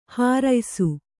♪ hāraysu